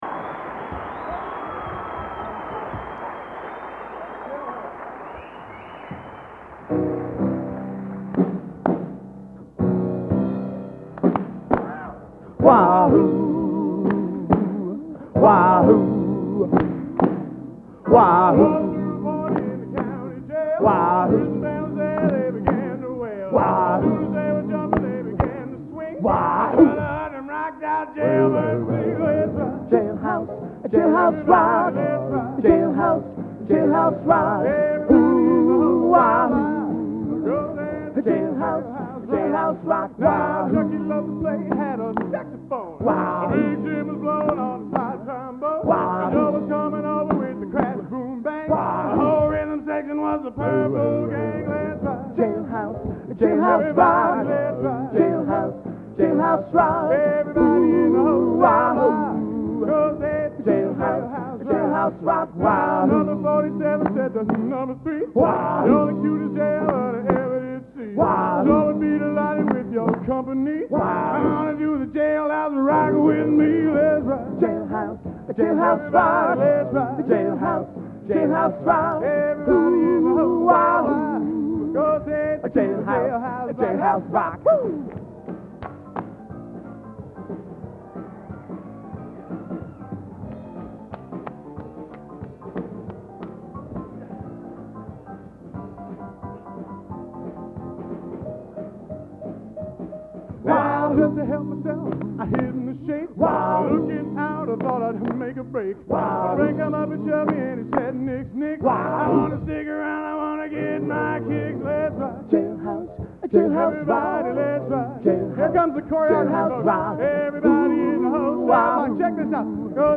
Location: West Lafayette, Indiana
Genre: Doo Wop | Type: End of Season |Specialty